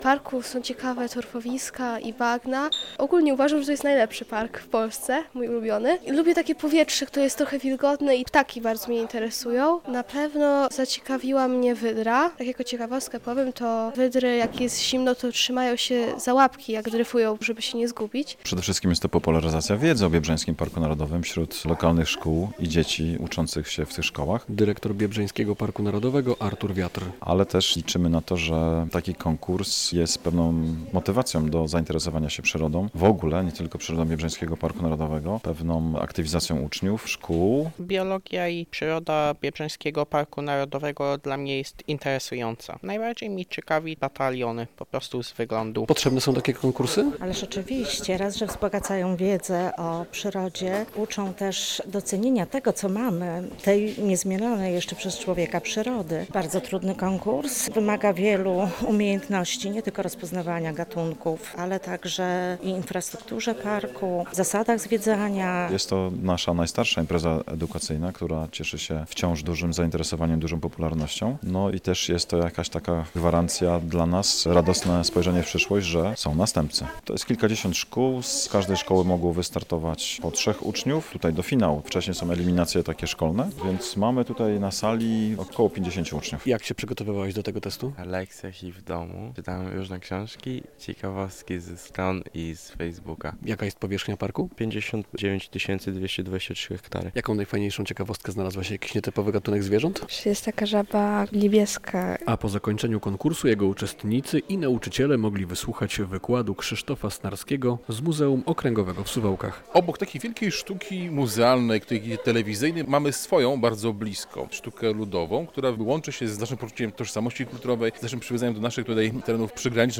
Uczniowie biorą udział w finale wojewódzkim konkursu wiedzy o Biebrzańskim Parku Narodowym - relacja
W siedzibie Biebrzańskiego Parku Narodowego, w Osowcu-Twierdzy, o tytuł najlepszego - w finale konkursu wiedzy o Biebrzańskim Parku - rywalizuje 50 uczniów.